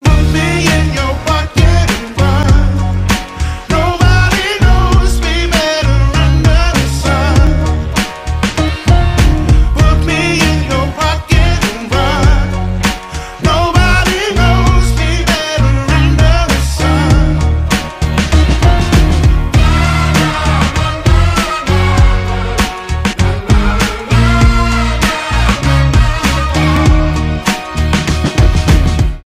alternative
поп